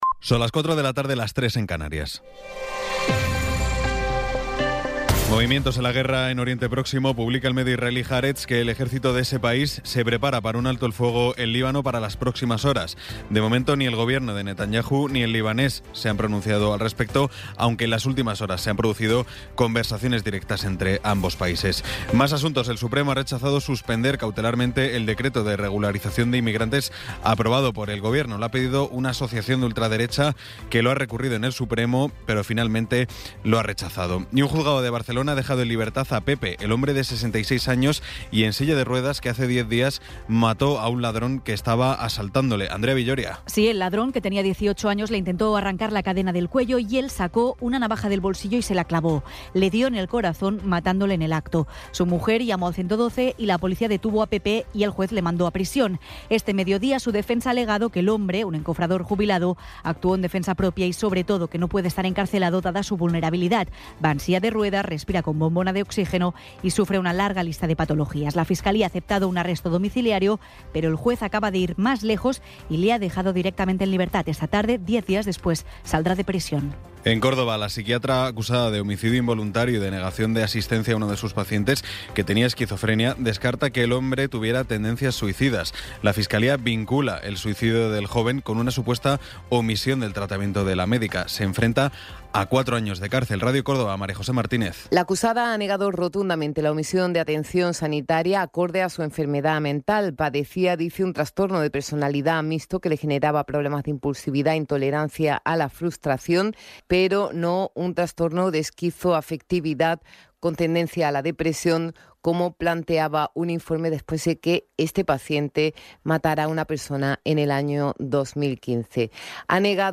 Resumen informativo con las noticias más destacadas del 16 de abril de 2026 a las cuatro de la tarde.